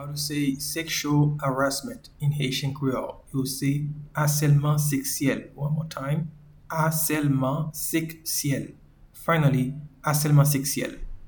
Pronunciation and Transcript:
Sexual-harassment-in-Haitian-Creole-Aselman-seksyel.mp3